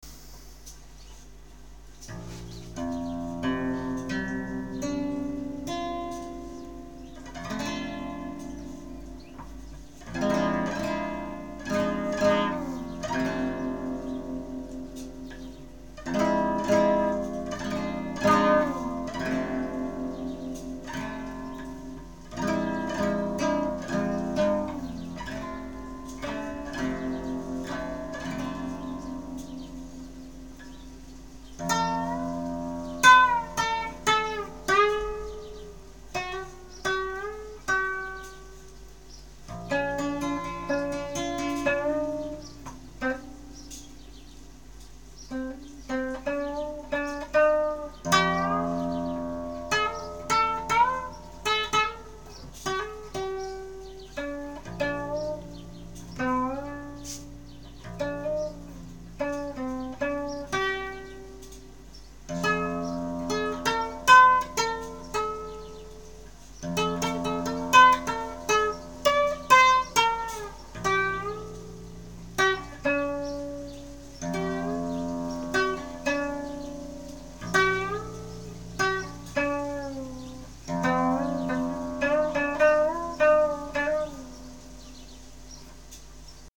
C-G-D-A-E-G
fretless nylon acoustic, and would be very glad to read your thoughts about it…”).